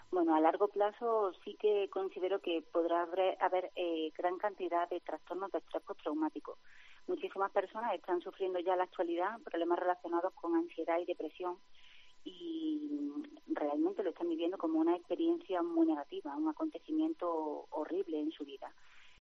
ENTREVISTA COPE ANDALUCÍA